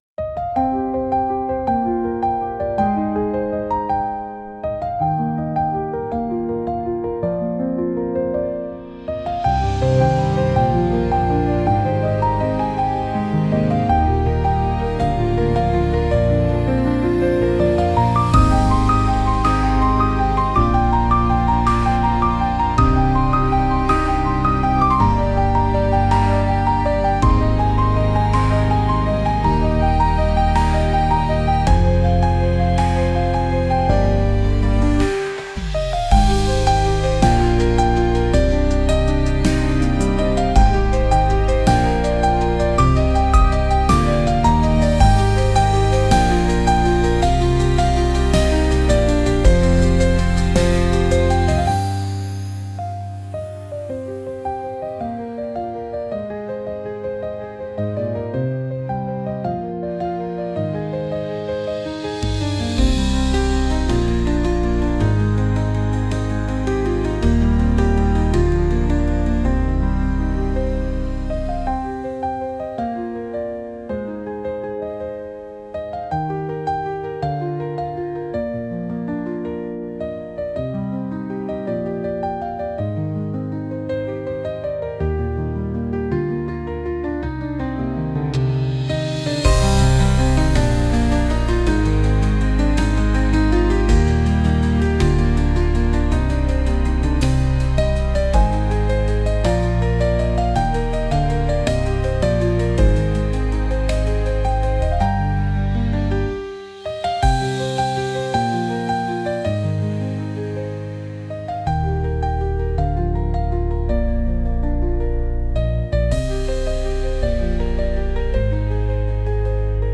Genre: Emotional Mood: Piano Editor's Choice